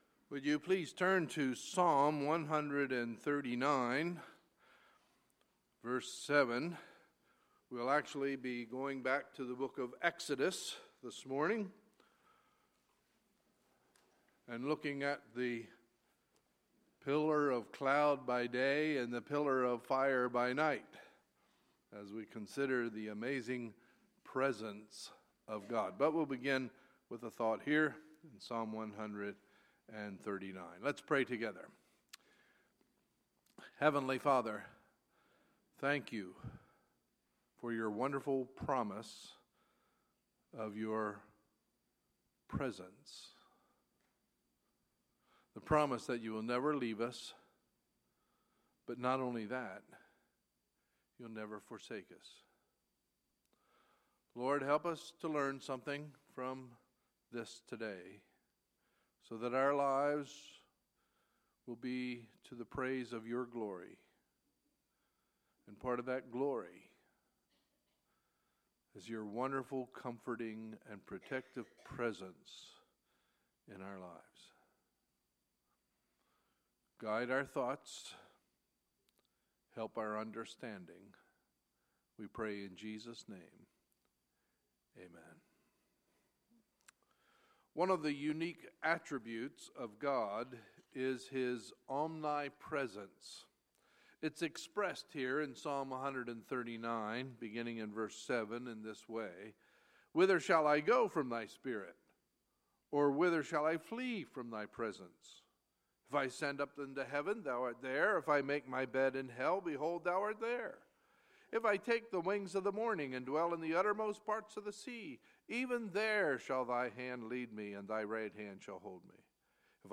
Sunday, June 12, 2016 – Sunday Morning Service